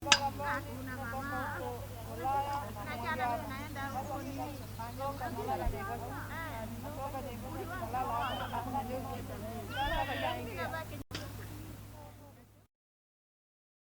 Village Talk
Village Talk is a free ambient sound effect available for download in MP3 format.
Village Talk.mp3